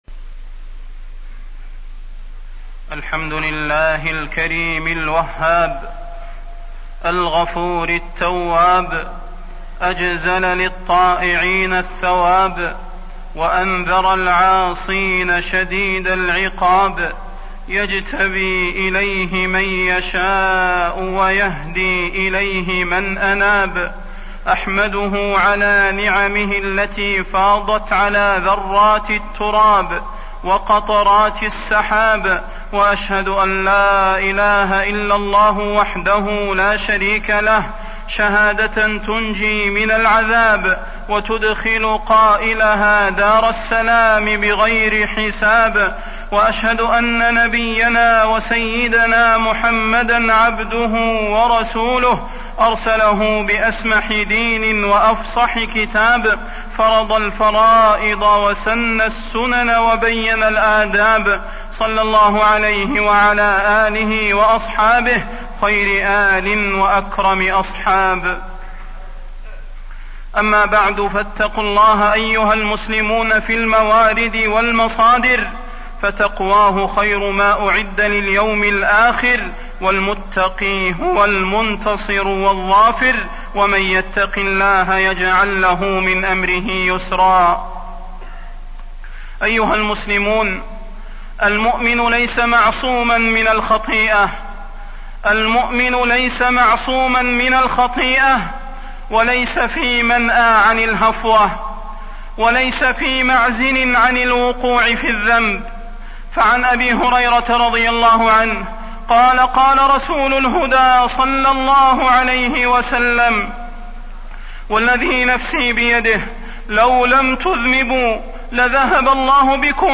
فضيلة الشيخ د. صلاح بن محمد البدير
تاريخ النشر ٢٨ ربيع الثاني ١٤٢٧ هـ المكان: المسجد النبوي الشيخ: فضيلة الشيخ د. صلاح بن محمد البدير فضيلة الشيخ د. صلاح بن محمد البدير التوبة